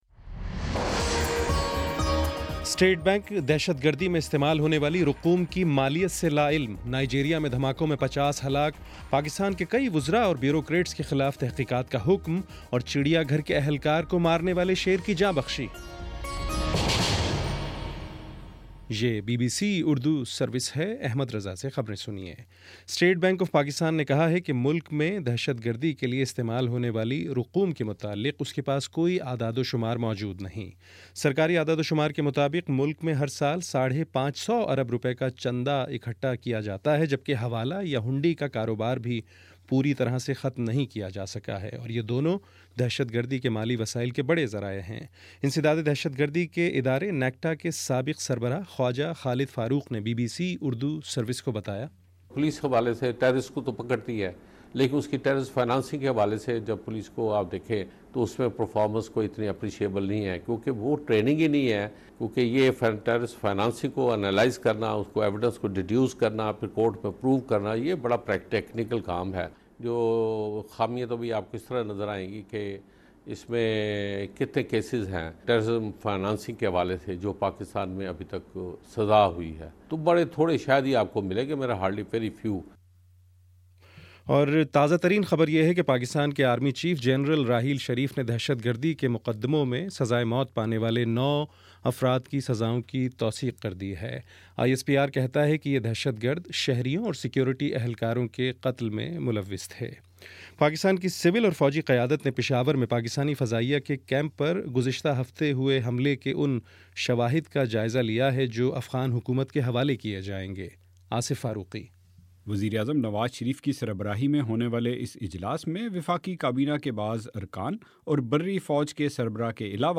ستمبر21 : شام سات بجے کا نیوز بُلیٹن